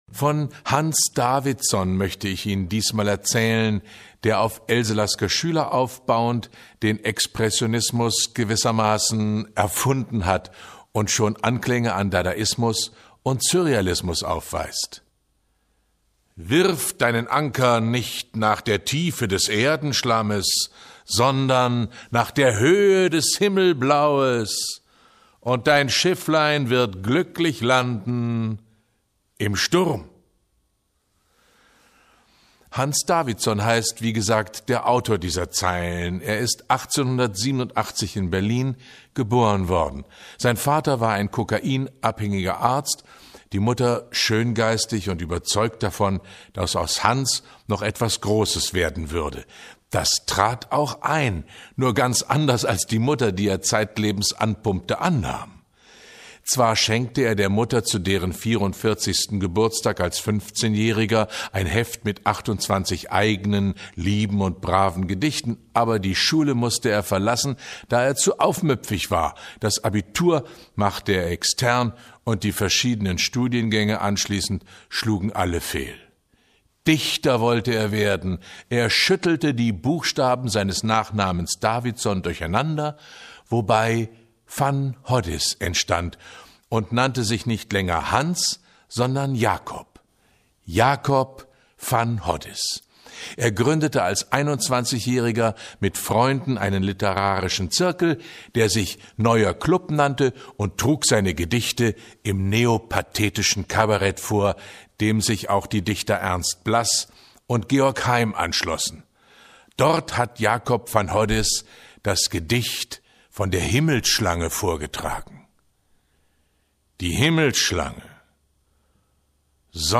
»Wir sind auf einem sehr guten Weg«: Interview mit Bürgermeisterin Katrin Wörpel